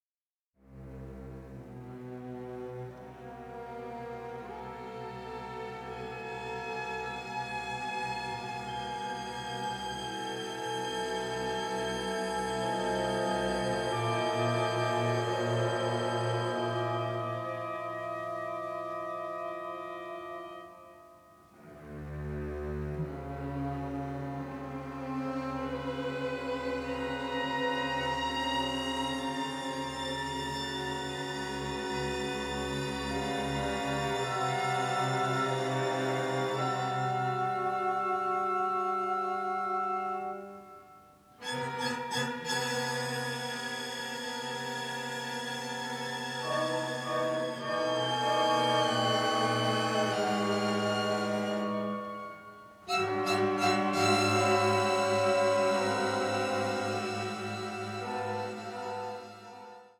in full stereo for the first time.
the melancholic, the macabre and the parodic